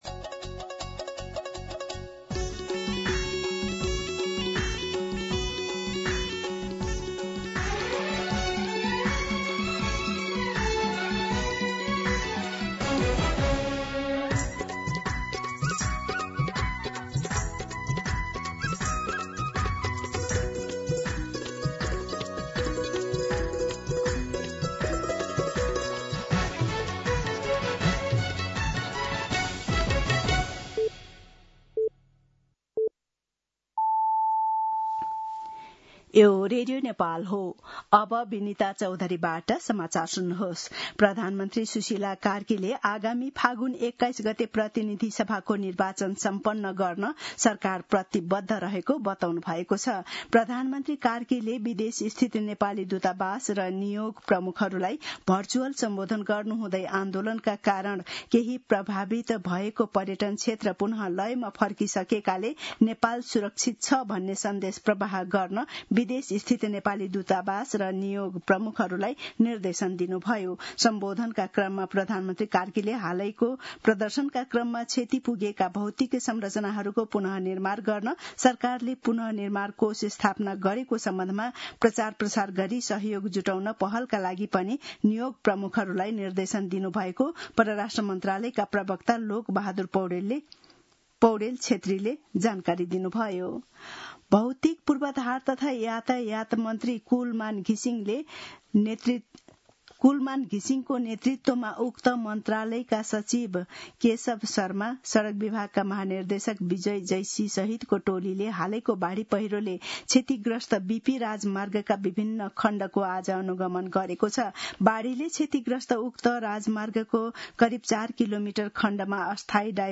मध्यान्ह १२ बजेको नेपाली समाचार : २३ असोज , २०८२